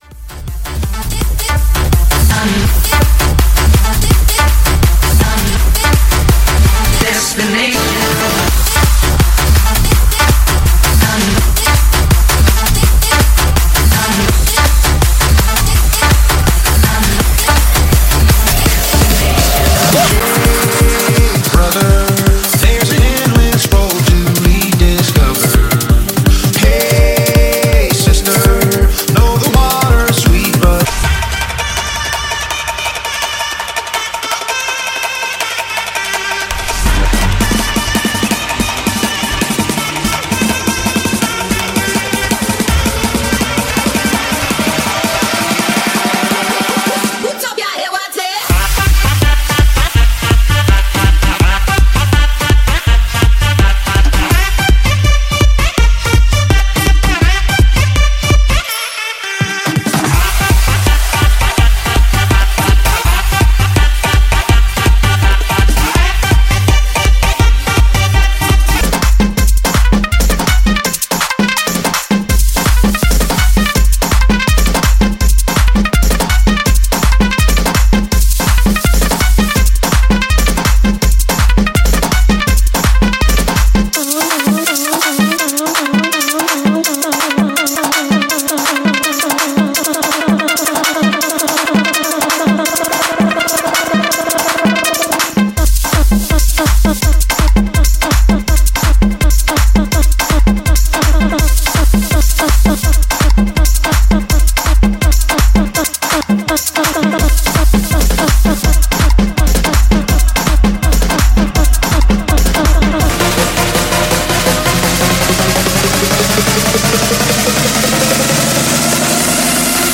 (32 count phrased) 160 BPM
dance, cardio, aerobics, Fitness…
60 minutes Tempo: 160 BPM Marque